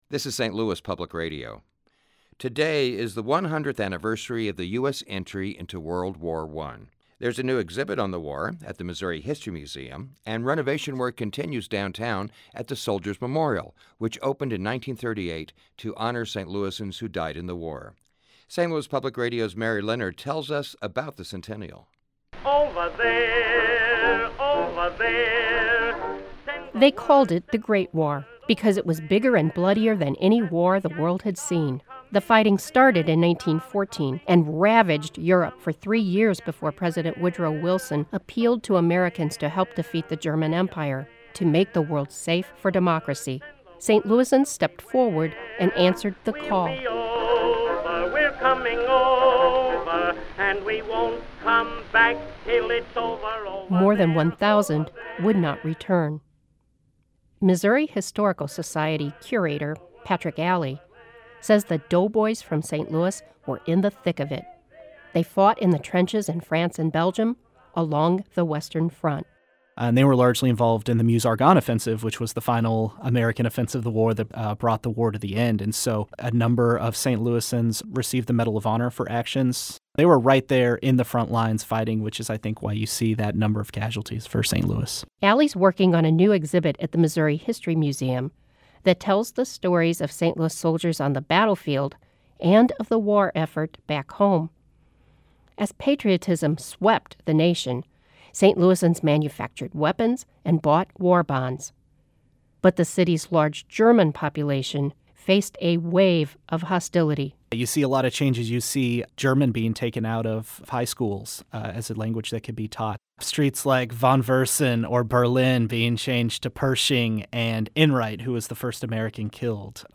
Listen to local historians discuss St. Louis in World War I.